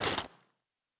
pgs/Assets/Audio/Fantasy Interface Sounds/Foley Armour 04.wav at master
Foley Armour 04.wav